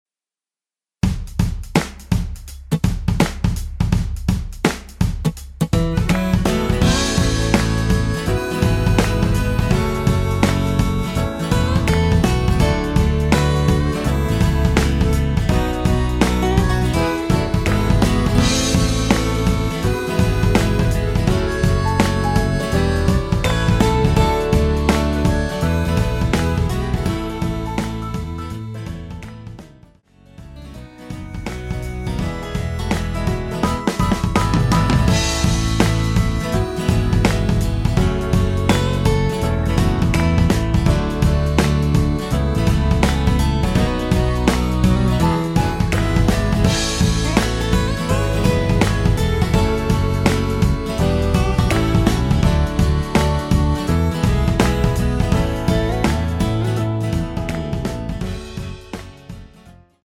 내린 MR
앨범 | O.S.T
◈ 곡명 옆 (-1)은 반음 내림, (+1)은 반음 올림 입니다.
앞부분30초, 뒷부분30초씩 편집해서 올려 드리고 있습니다.